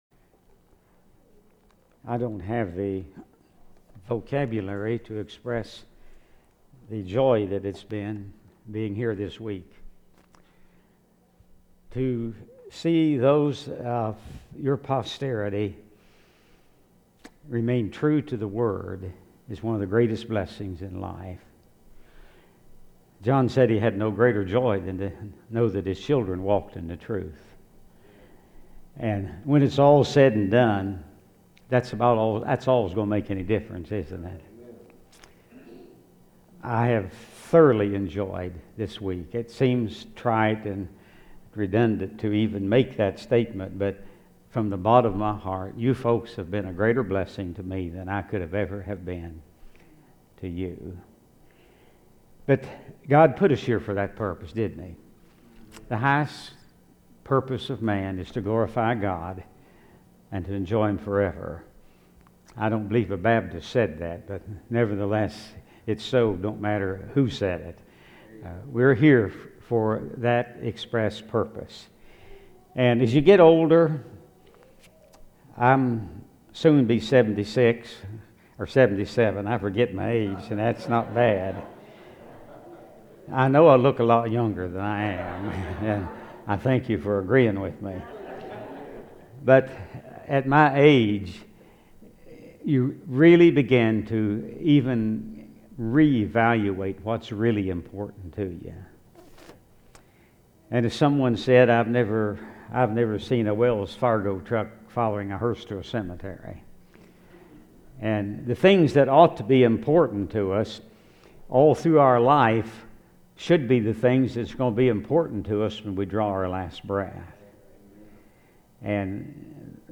Service Type: Revival 2012